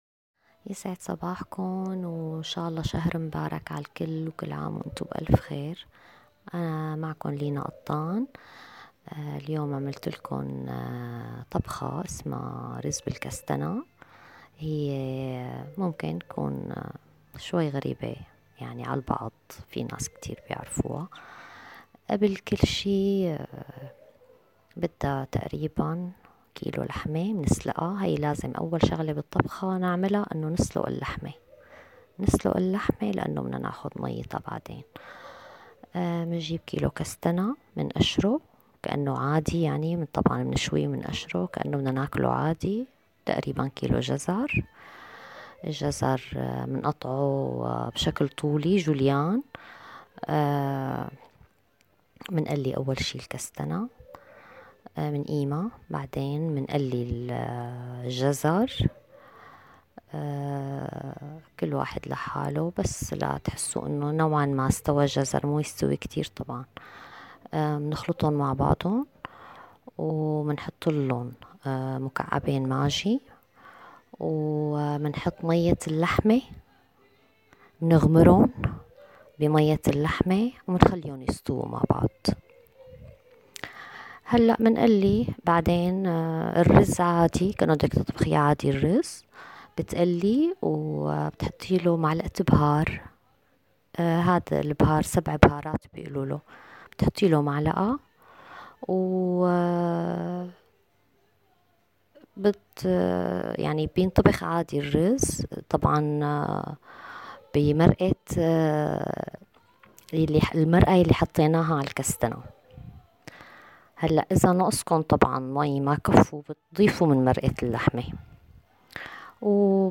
اليوم معنا سيده دمشقيه أصيله ومميزة بطبخاتا ونفسها الطيب